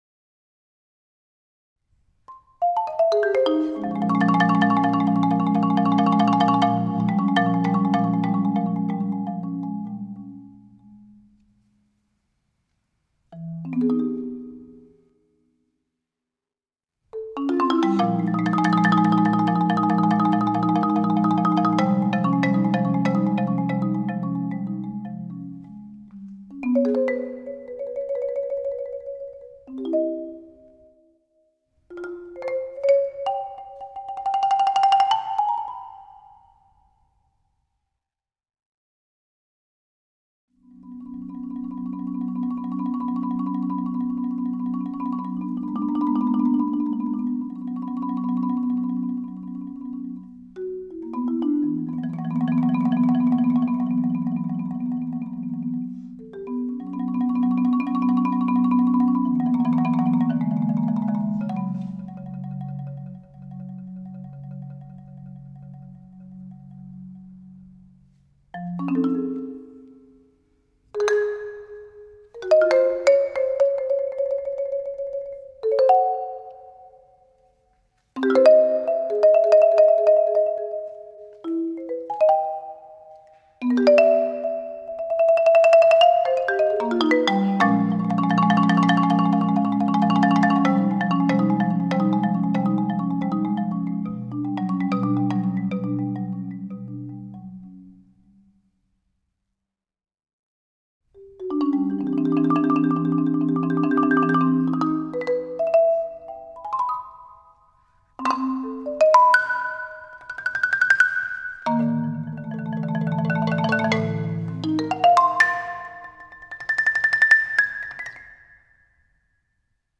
Instrumentation: solo marimba